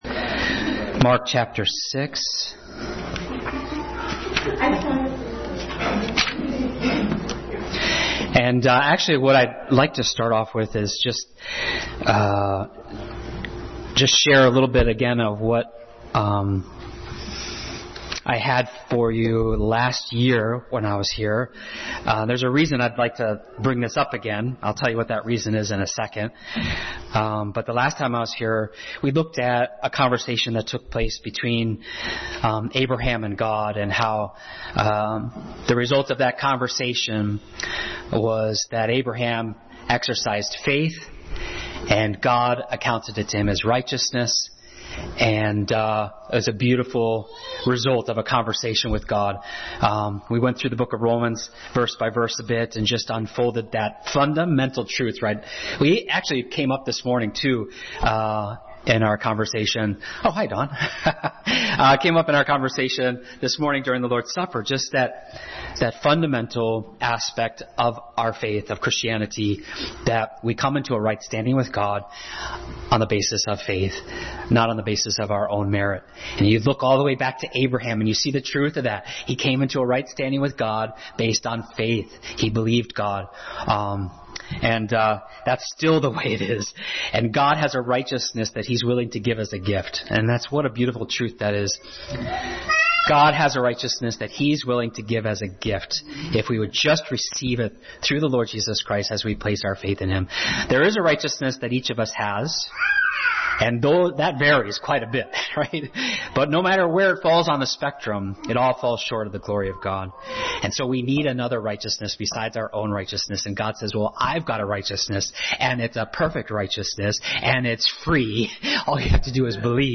Passage: Mark 6:4, Hebrews 12:1, Leviticus 26:14-45, Service Type: Sunday School